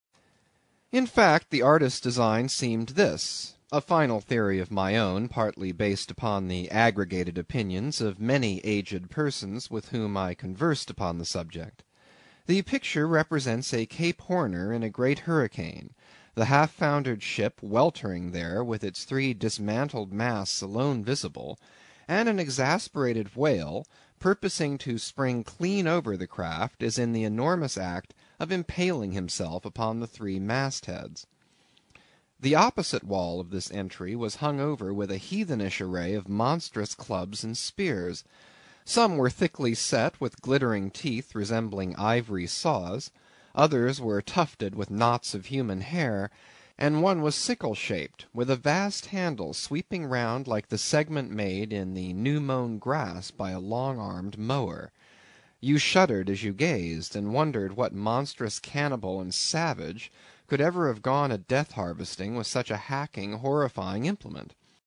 英语听书《白鲸记》第190期 听力文件下载—在线英语听力室